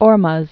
(ôrmŭz, ôr-mz), Strait of